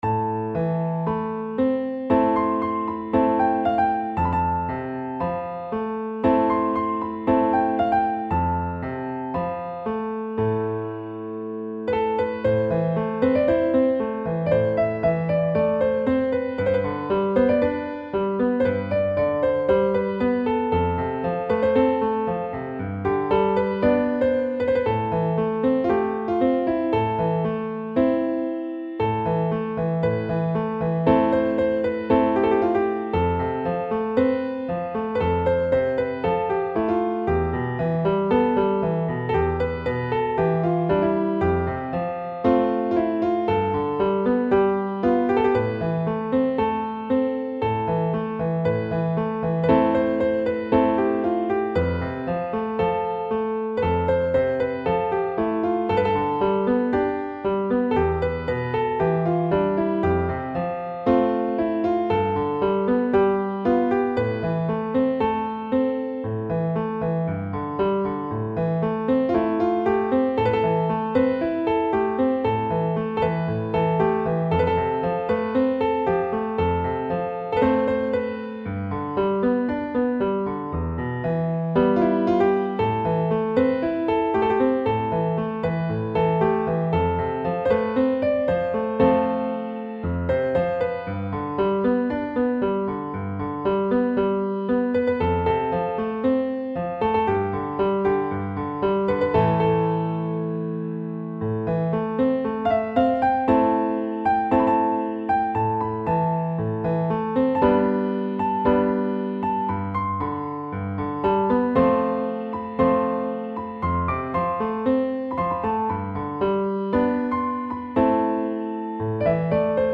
نت پیانو